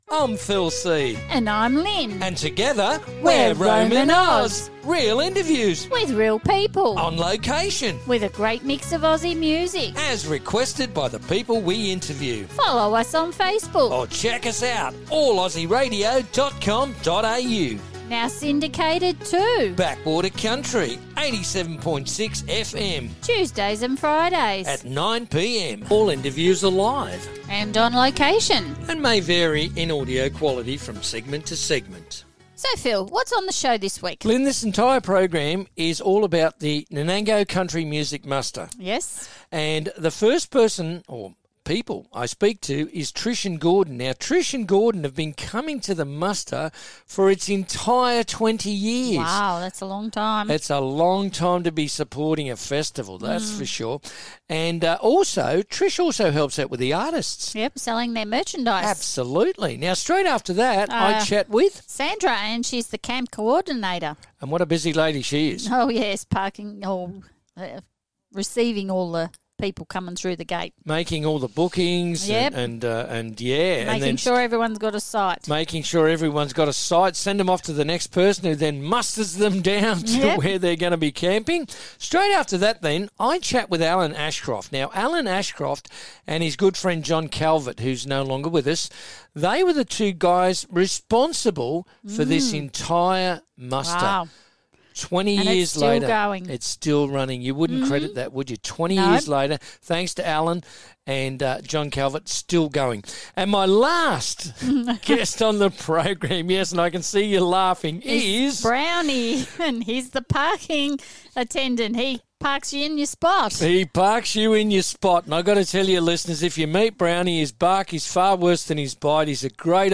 On the show we are at Nanango at the Country Music Muster.